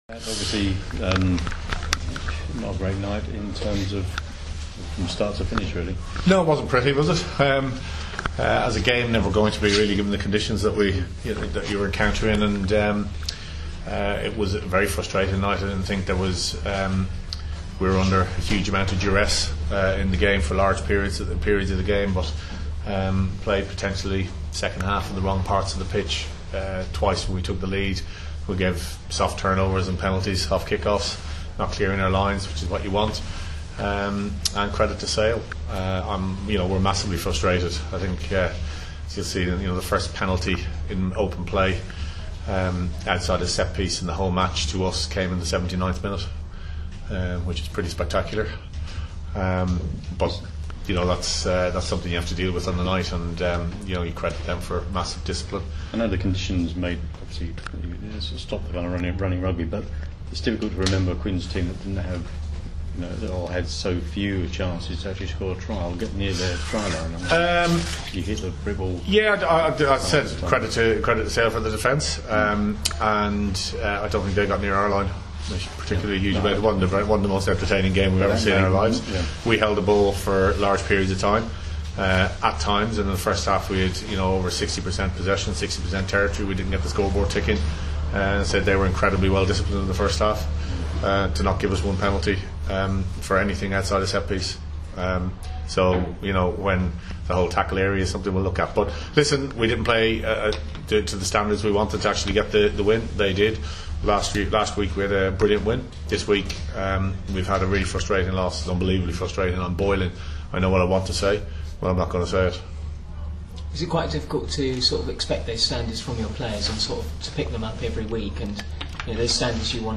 Conor O'Shea, speaking after Harlequins loss to Sale Sharks.